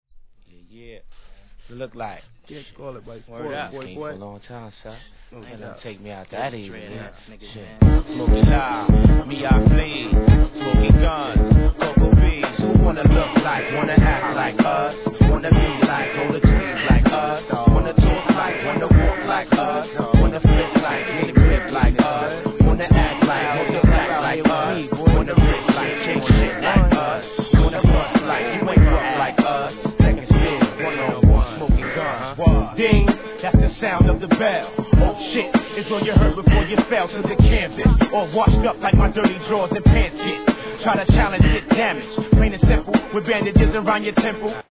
HIP HOP/R&B
癖になるBEATに疾走感有るRAPで畳み込む様がカッケ〜す!!